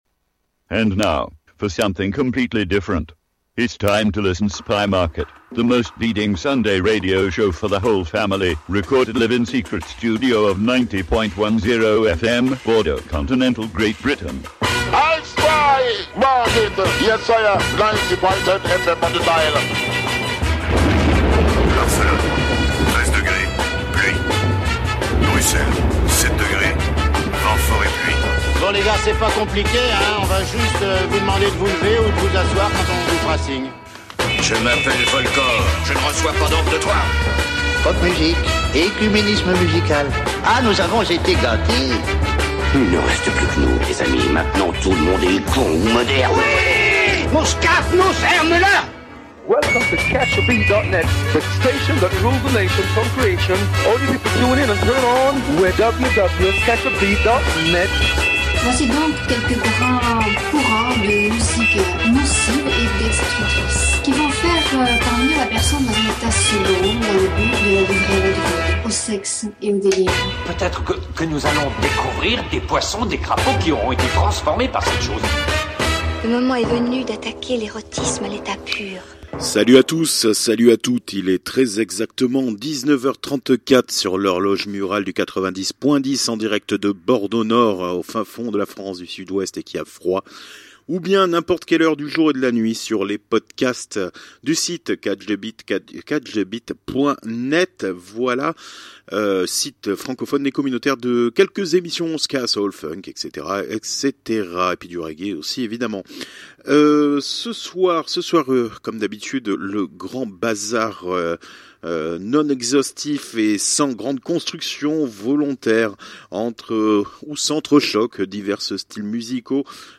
Spy Market Radio show reçoit l’association « Wild Beats Club » la relève Bordelaise, dans la promotion des soirées 100 % Vinyles // Spy Market welcome « Wild Beats club » , the new breed of Bordeaux’ 100% Vinyl Dj live sessions promoters…